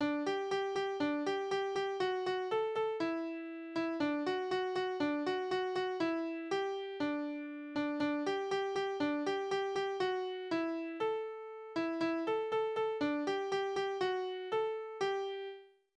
Kinderlieder: Bub und Spinne
Tonart: G-Dur
Taktart: 2/4
Tonumfang: Quinte
Besetzung: vokal